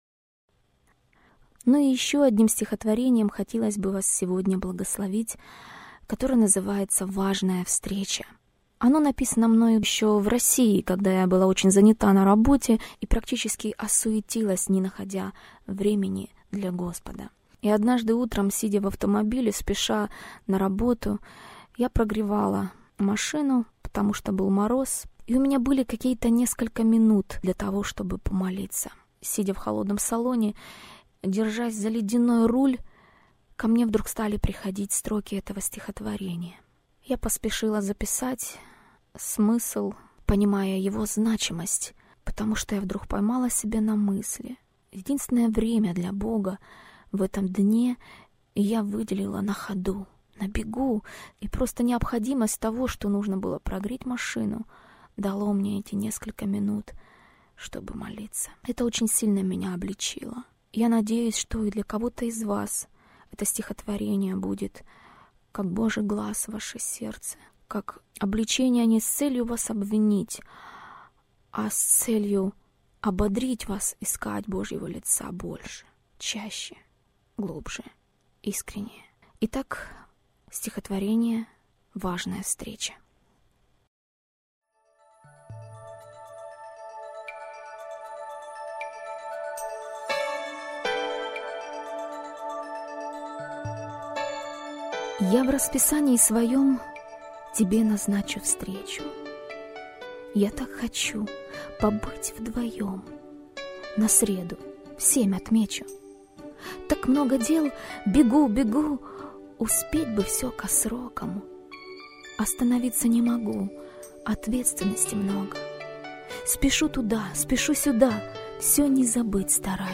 Поэтическая радиопрограмма Прикосновение